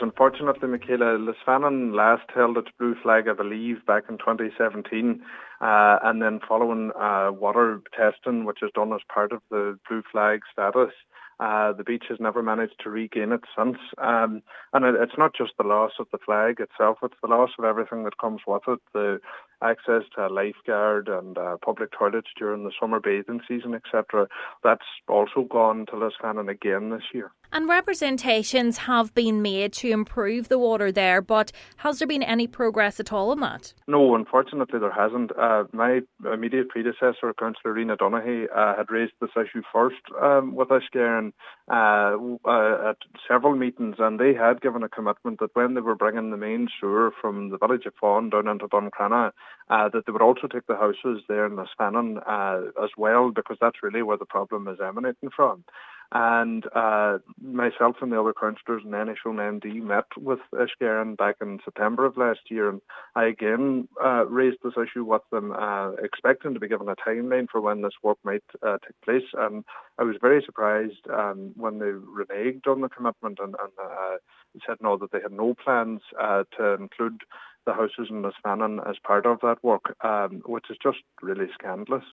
He says it’s scandalous that no progress has been made to develop plans to resolve the issue: